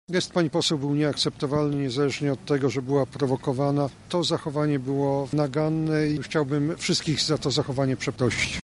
W swoim przemówieniu Jarosław Gowin- wicepremier i prezes partii Porozumienie podkreślił fakt szybkiego rozwoju gospodarczego Polski w ostatnich latach oraz bezpieczeństwa zarówno wewnętrznego jak i międzynarodowego.